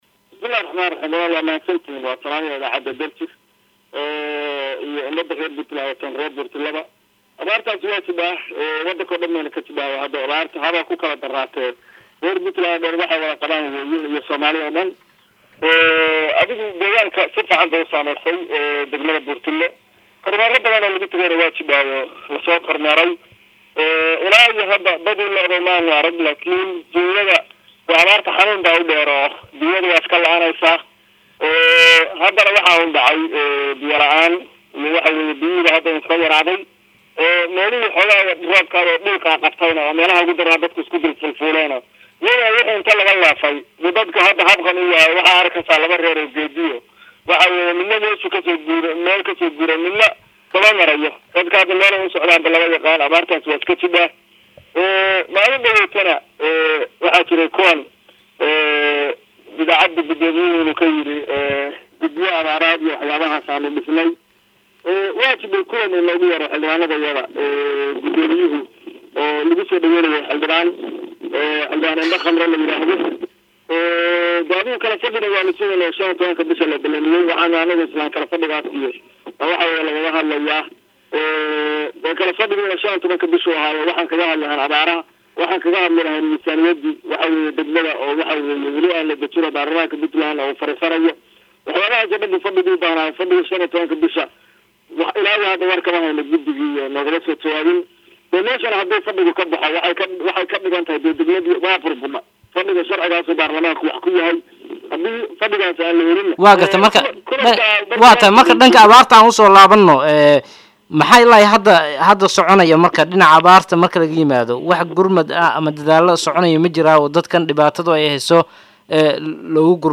November 28 206 (Puntlandes)-Xildhibaan Axmed Cabdulaahi Ilwayn o katirsan golaha deegaanka ee degmada Burtinle ayaa sheegay inuu dib u dhac ku yimid fadhigii golaha deedaanka ee degmada oo la filayay 15ka bishaan inuu furmo.